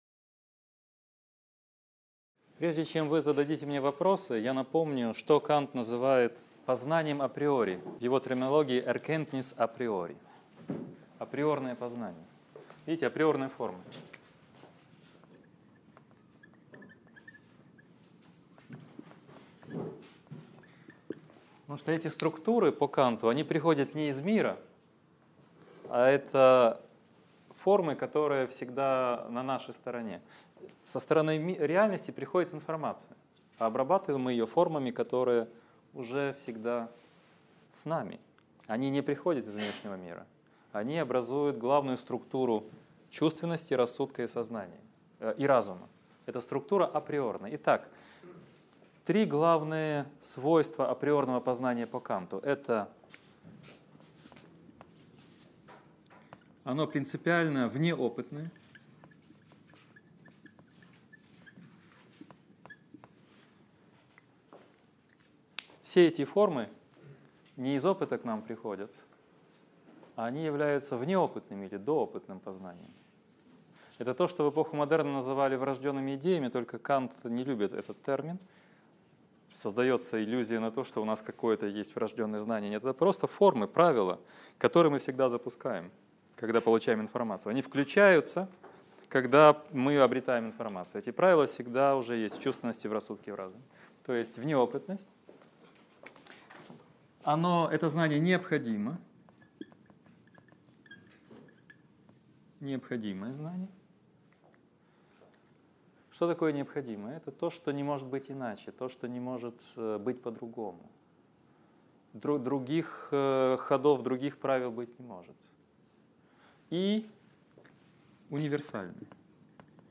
Аудиокнига Лекция 12. Трансцендентальная философия Канта в главных чертах | Библиотека аудиокниг